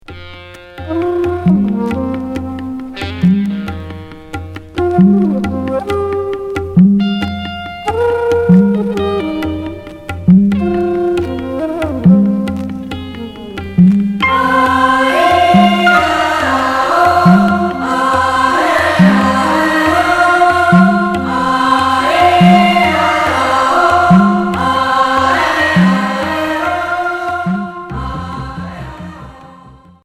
Pop progressif Unique 45t retour à l'accueil